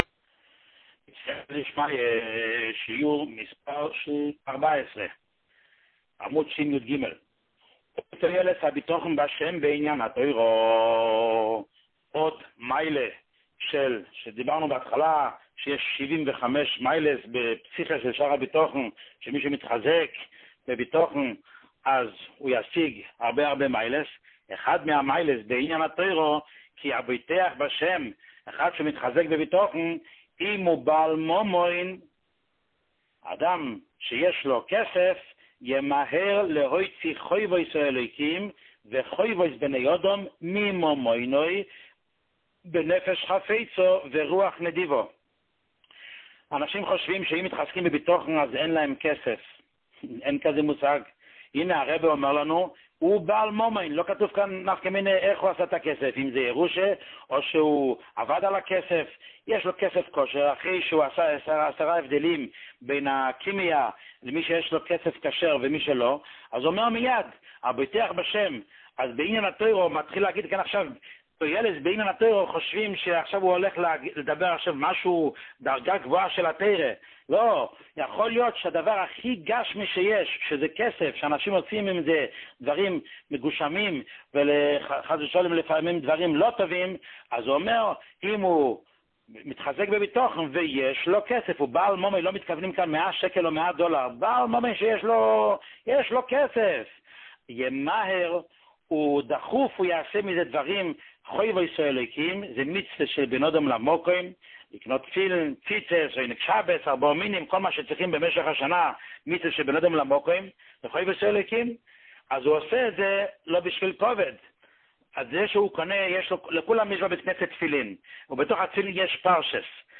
שיעור 14